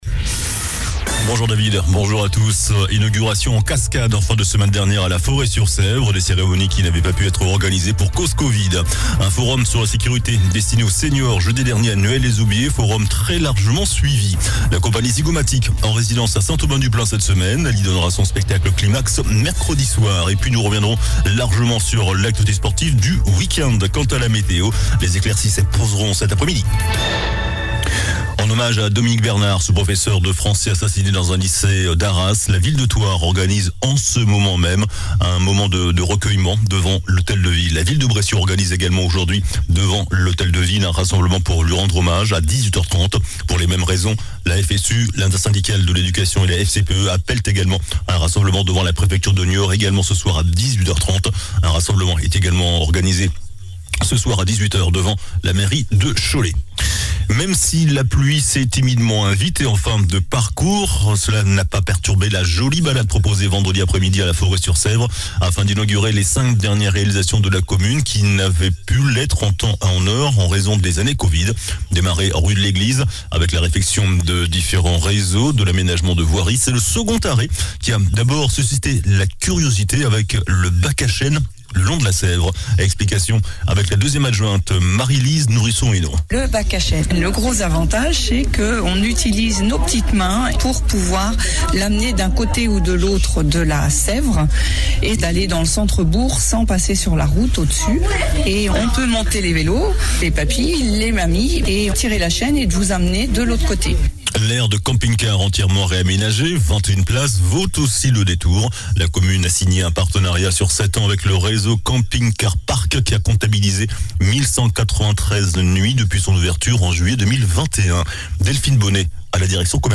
JOURNAL DU LUNDI 16 OCTOBRE ( MIDI )